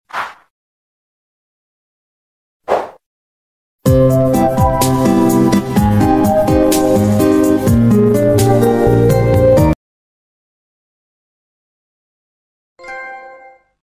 Sony PlayStation Trophy Animation Evolution